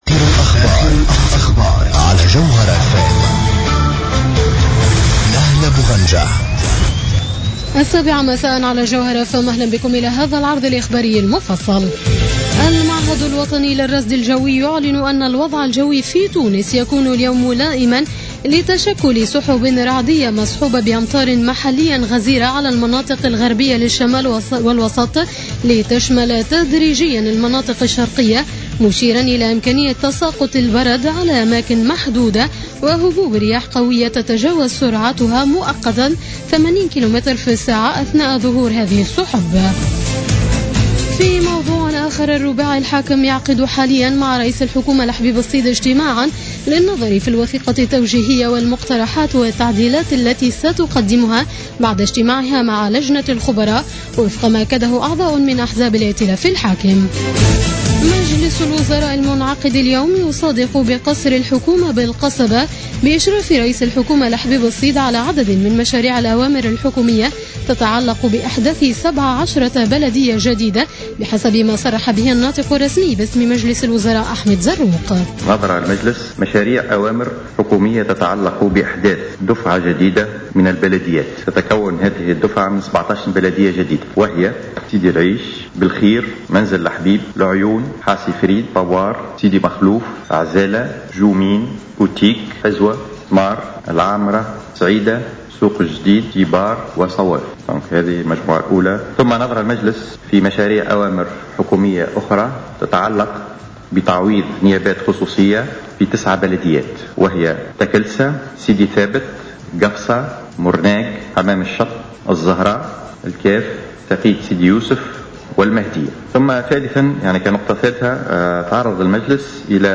نشرة أخبار السابعة مساء ليوم الإربعاء 2 سبتمبر 2015